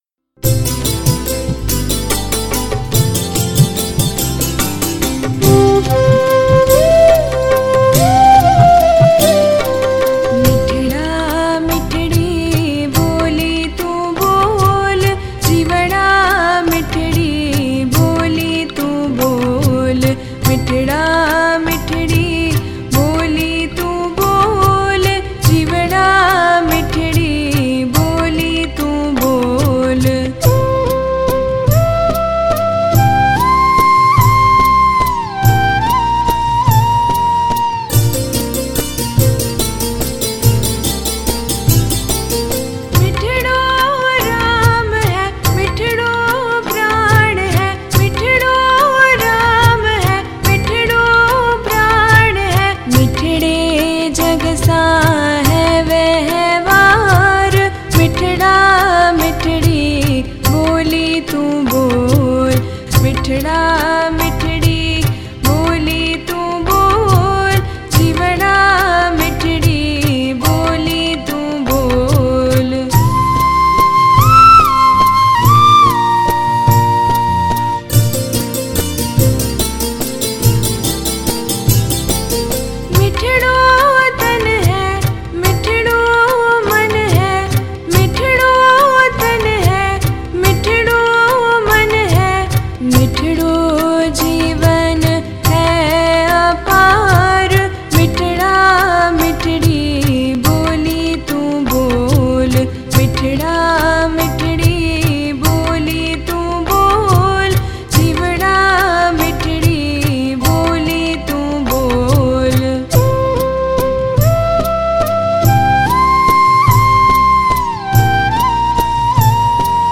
Hymns
Recorded at: Line-In Studio